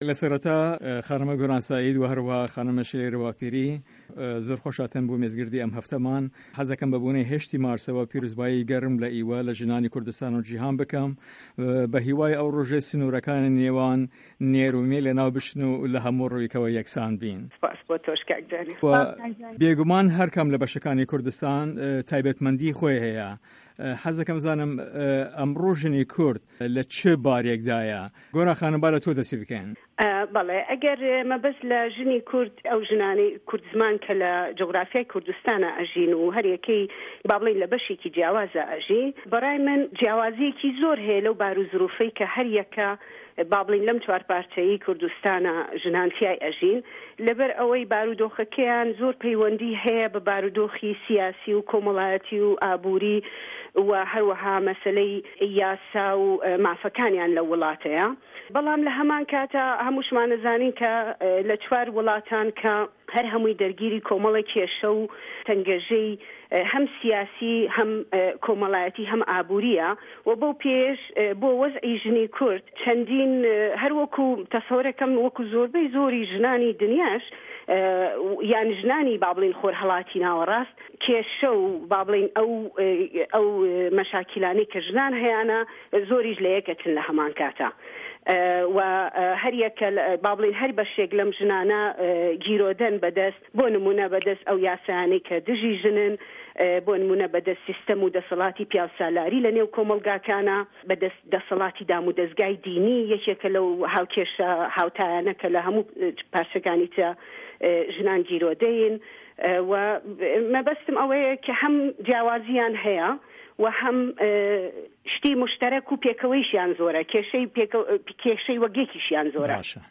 Round table JD